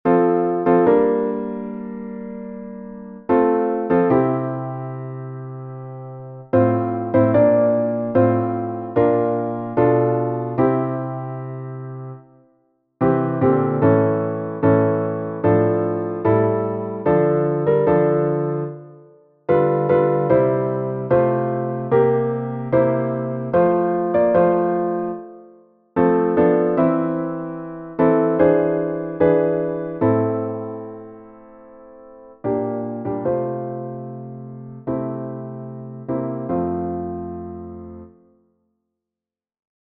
das Wiegenlied - ukolébavka
Protože jsem bohužel neobjevil žádný vokální ani instrumentální záznam písně, přepsal jsem nalezené notové zánamy a z nich vytvořil zvukové záznamy melodie.
Čtyřčtvrteční varianta, byla vydána v roce 1895, hudba Wilhelm Taubert